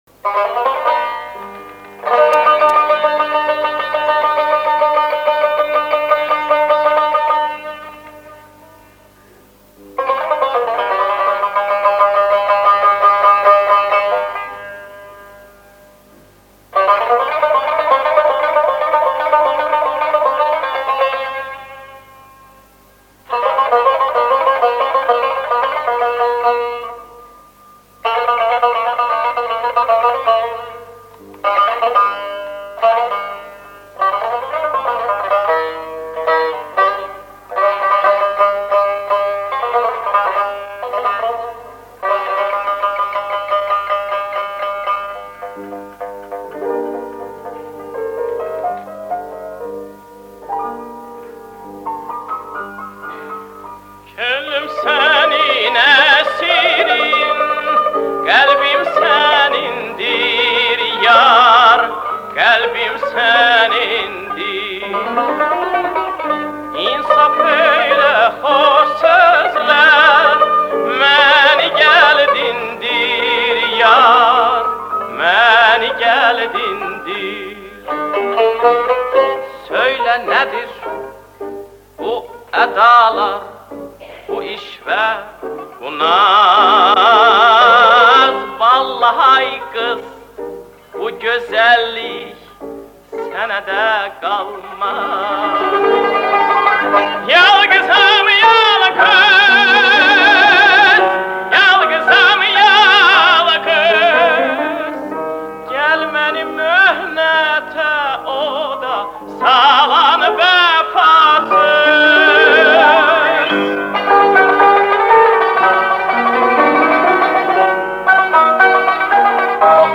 лирический тенор